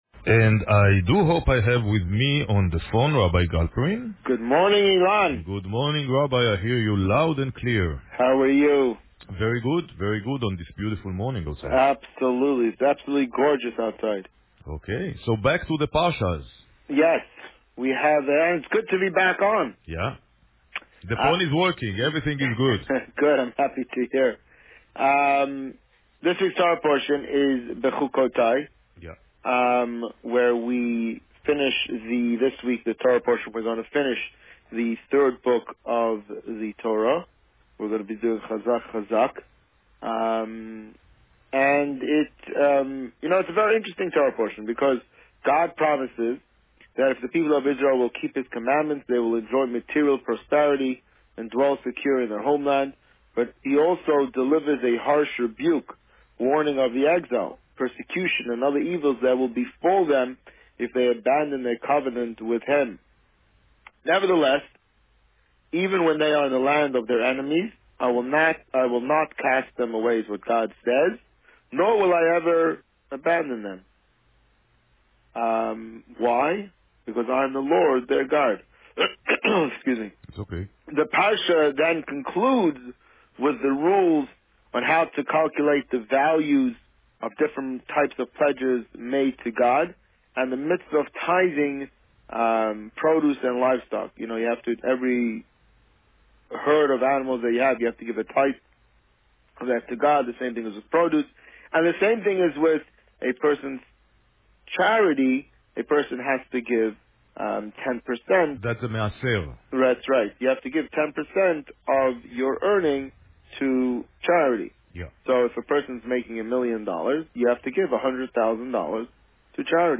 This week, the Rabbi spoke about parsha Bechukotai. Listen to the interview here.